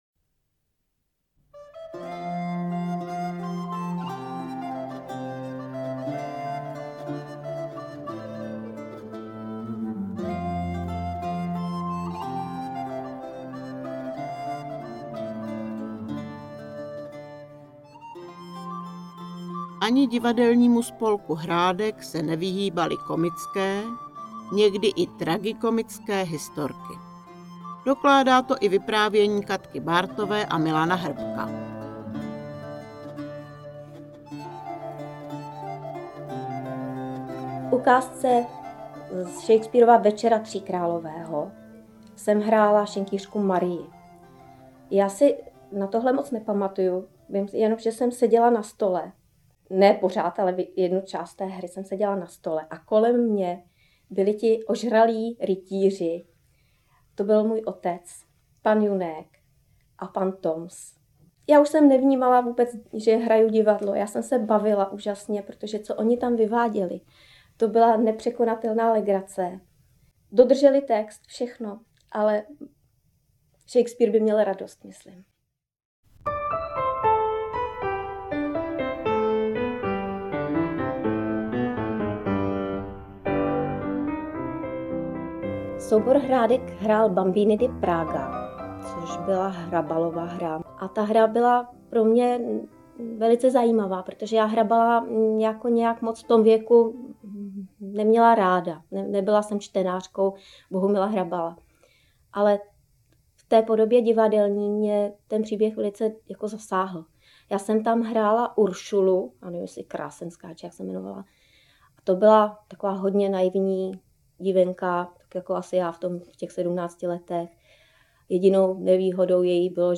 Ve stopách Thalie, Vzpomínky - bonus je audio přílohou dvoudílného filmového průvodce po historii ochotnického divadla v městysi Křivoklát. Komentované listinné a obrazové dokumenty, vzpomínky křivoklátských ochotníků.